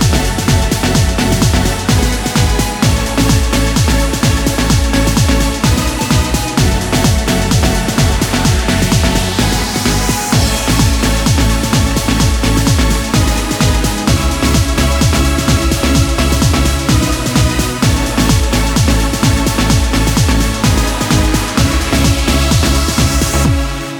No Intro Vocals Pop (2010s) 3:19 Buy £1.50